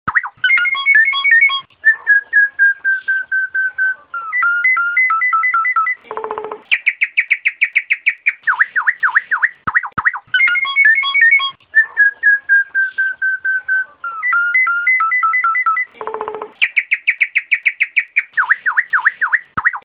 Download Suara Jalak Nias Gacor Super - kumpulan masteran burung jalak nias jantan super gacor dengan volume keras dan variasi isian suara yang beragam
Download Masteran Jalak Nias